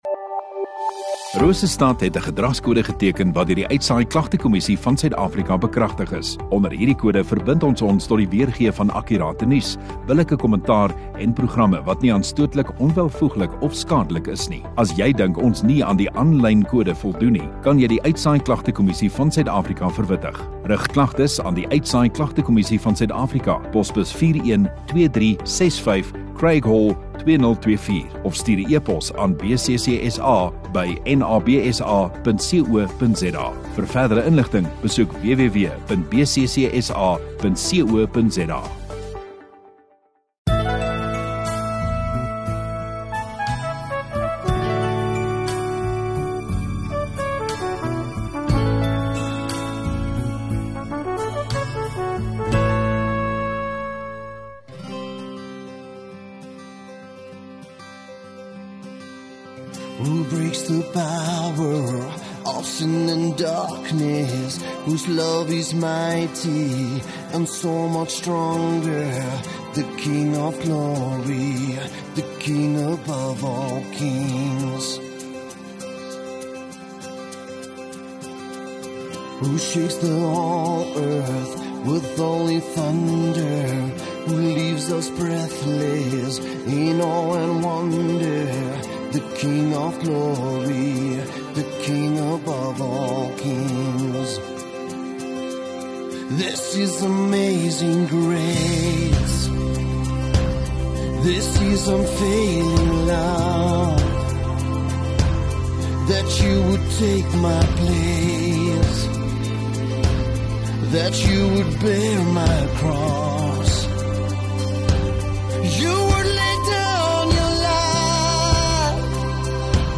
Rosestad Godsdiens 7 Dec Sondagaand Erediens